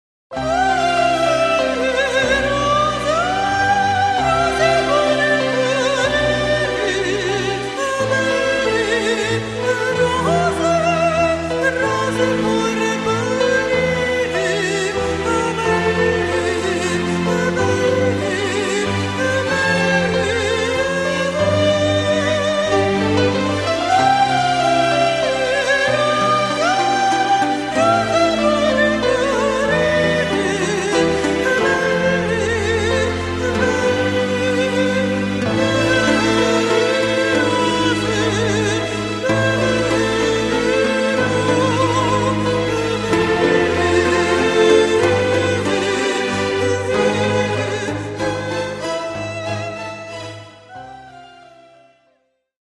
Notturno